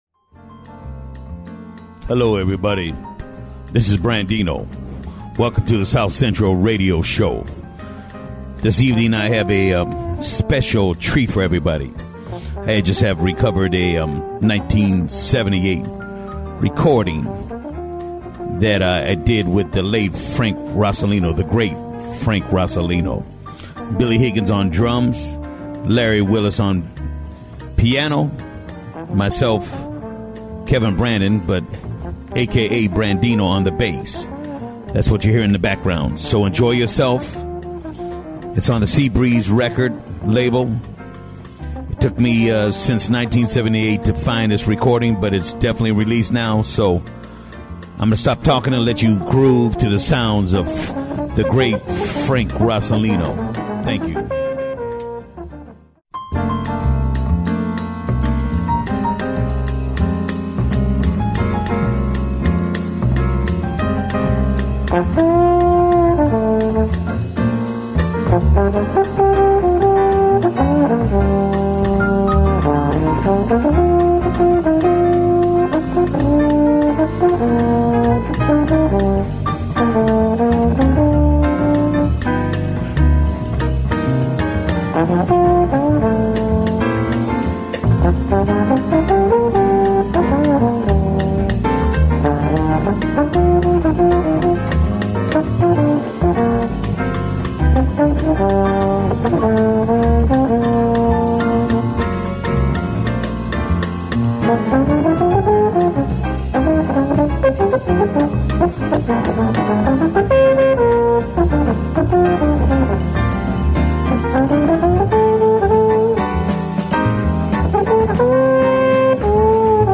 Drums
Piano
Bass